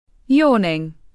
Yawning /ˈjɔː.nɪŋ/